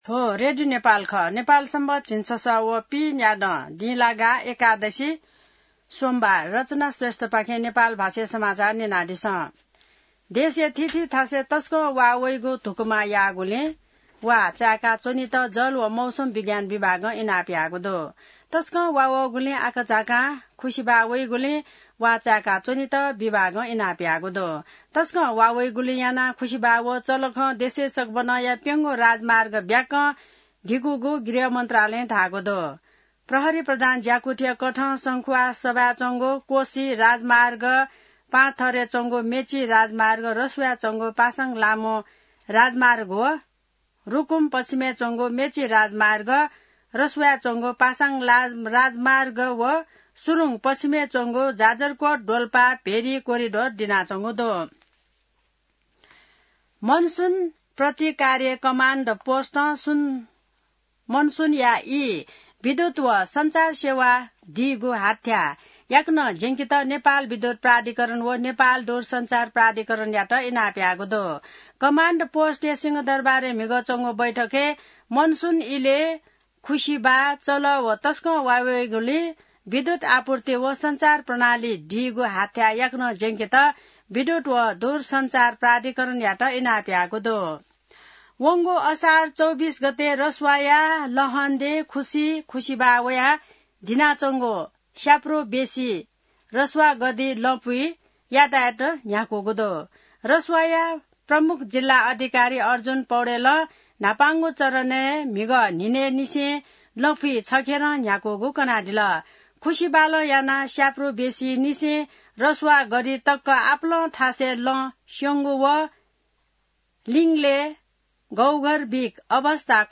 An online outlet of Nepal's national radio broadcaster
नेपाल भाषामा समाचार : ५ साउन , २०८२